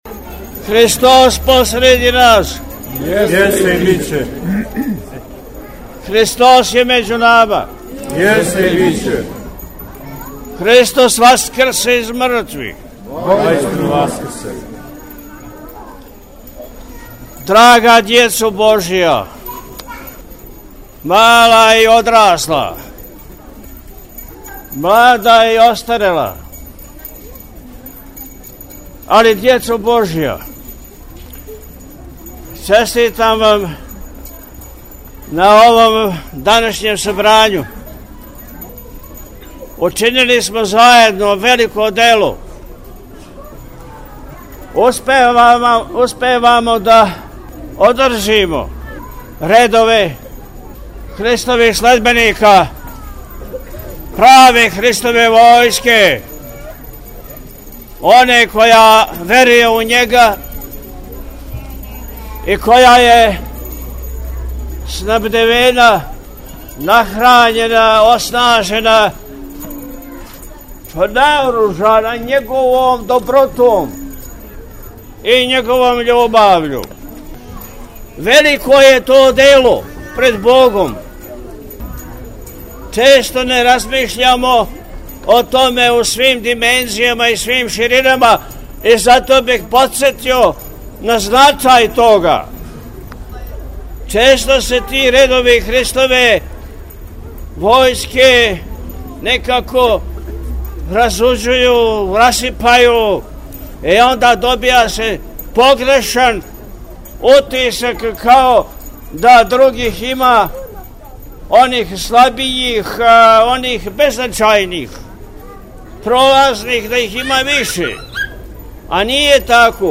Саборни храм Светог Василија Острошког у Пријепољу у навечерје празника Уласка Господа Исуса Христа у Јерусалим – Цвети, 12. априла 2025. године, сабрао је под својим сводовима велики број деце која су са својим родитељима дошла да заједно са Епископом, свештенством и верним народом дочекају овај велики Празник.